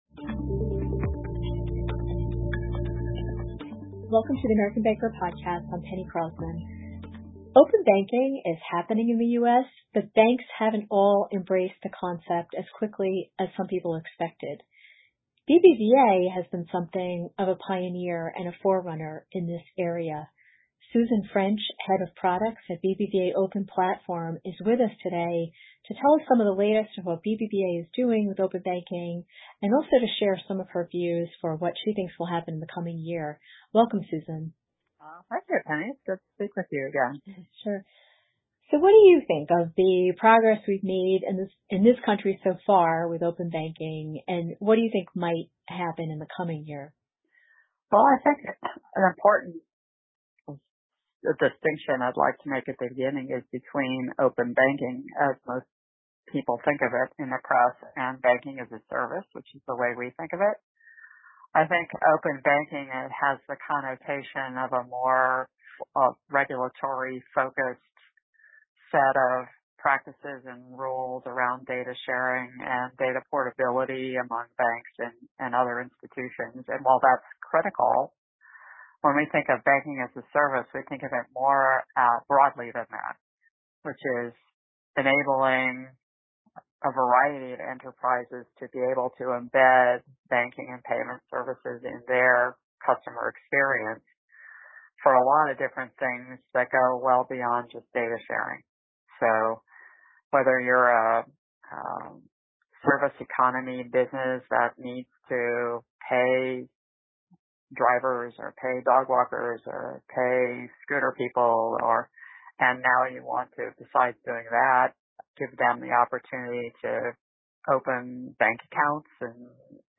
Today we invite you to join a discussion with three global leaders on the forefront of the COVID-19 pandemic as they discuss both the public health and economic impact of the virus.